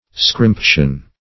Search Result for " scrimption" : The Collaborative International Dictionary of English v.0.48: Scrimption \Scrimp"tion\, n. A small portion; a pittance; a little bit.